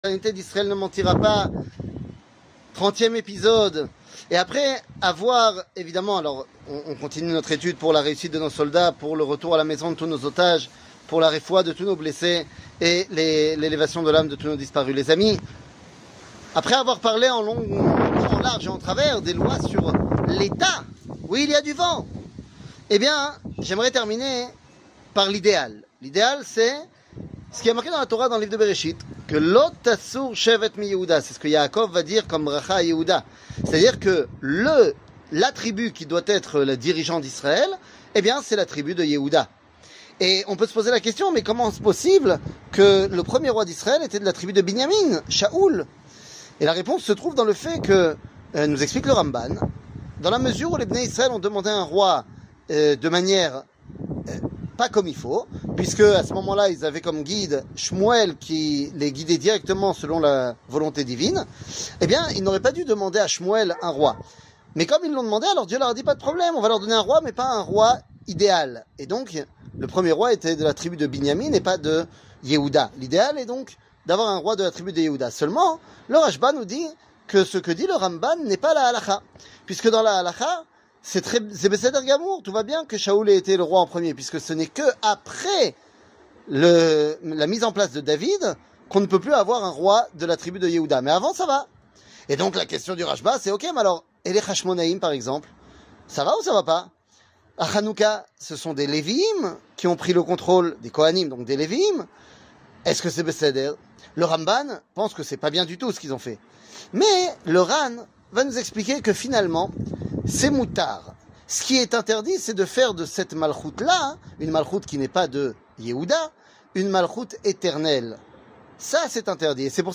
L'éternité d'Israel ne mentira pas ! 31 00:03:34 L'éternité d'Israel ne mentira pas ! 31 שיעור מ 19 נובמבר 2023 03MIN הורדה בקובץ אודיו MP3 (3.27 Mo) הורדה בקובץ וידאו MP4 (7.39 Mo) TAGS : שיעורים קצרים